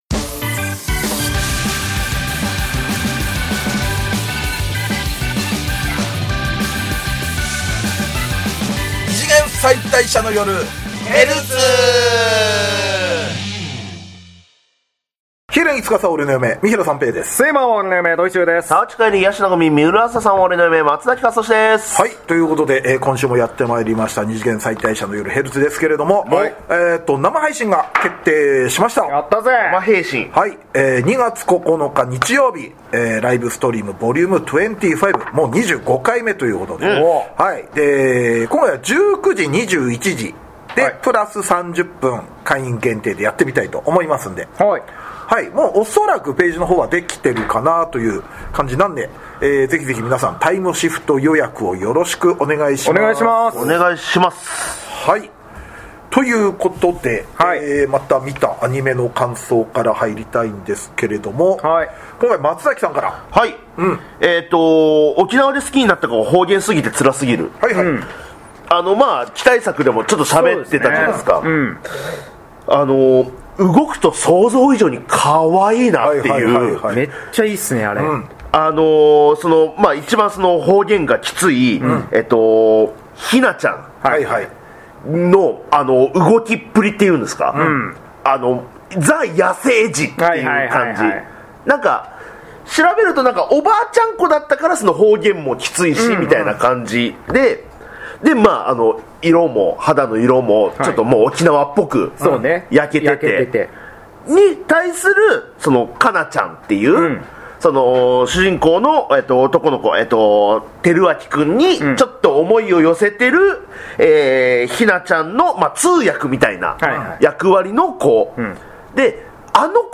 旬なテレビアニメの感想話やオススメ漫画話で楽しく陽気にバカ話！二次元キャラクターを嫁に迎えた芸人３人による、キャラ萌え中心アニメトークポッドキャストラジオです！